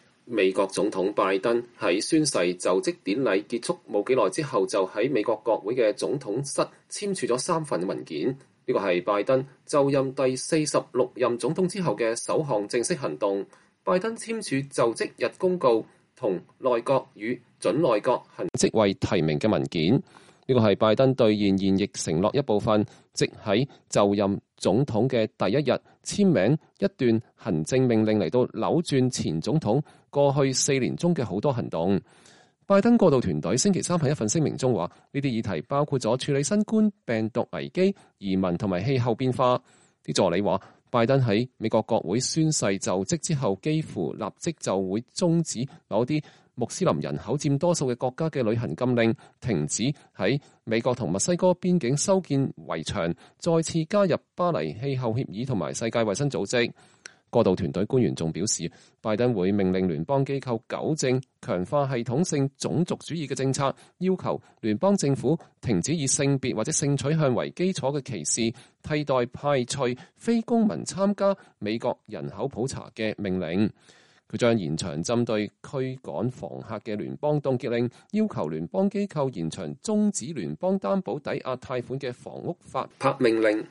美國總統拜登發表就職演說（2021年1月20日）